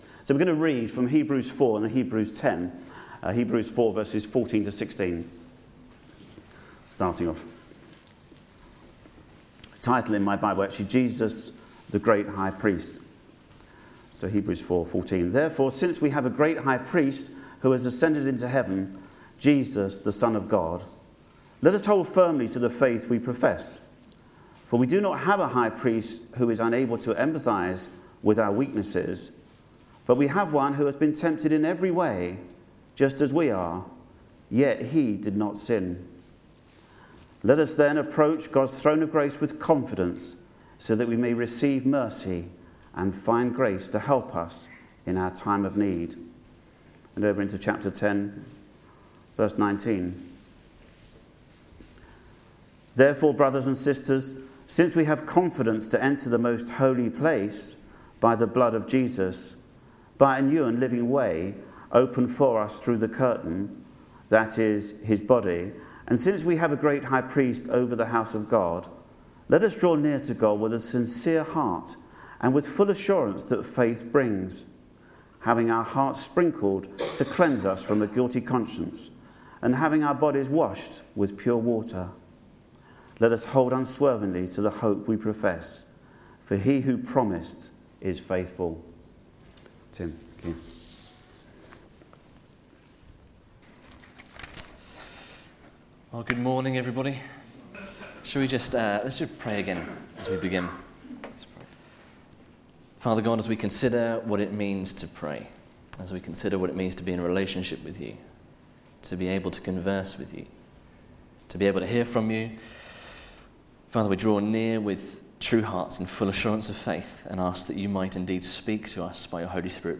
Media Library Media for Sunday Service on Sun 09th Feb 2014 10:00 Speaker
Prayer Series Theme: What is prayer and how does it work? Handout Open Sermon To find a past sermon use the search bar below You can search by date, sermon topic, sermon series (e.g. Book of the Bible series), bible passage or name of preacher (full or partial) .